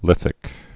(lĭthĭk)